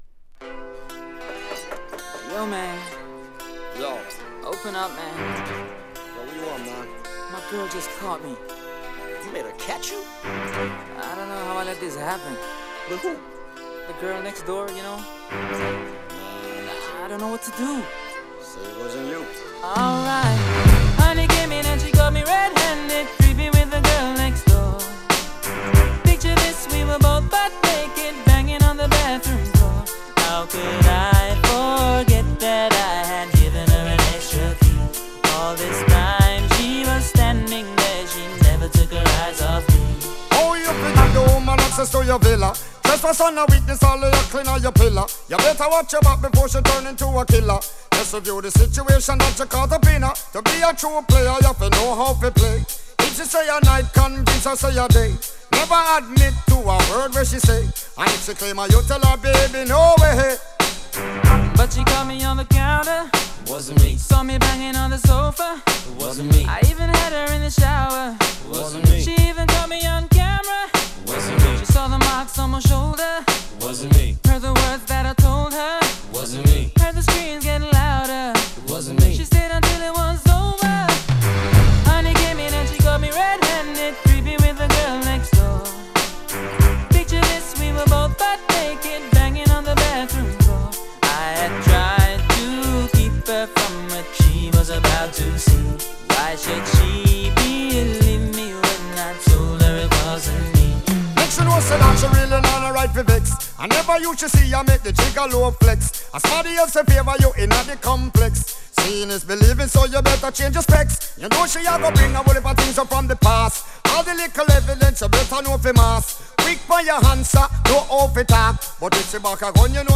ラジオ等でもヘヴィローテされたこの曲、爽やかなトラックに絡む歌声！スムースなR&Bが好きな人は要チェック！